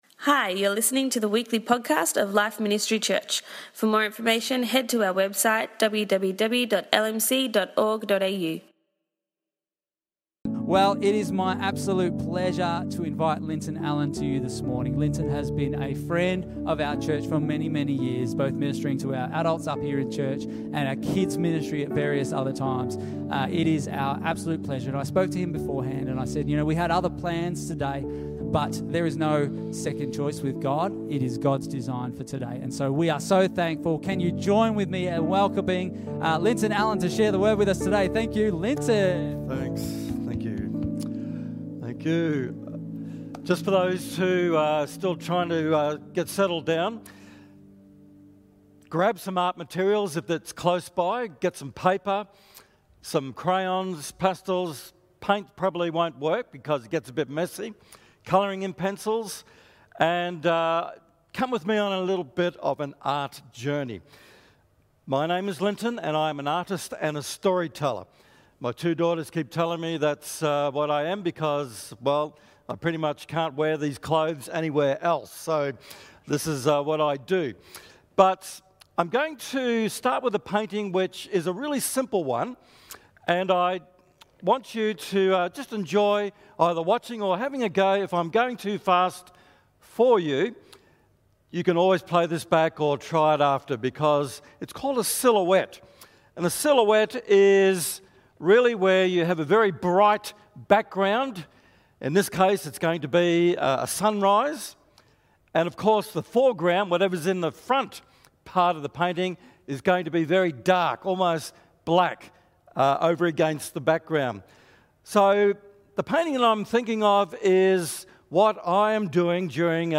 We heard a visual and creative message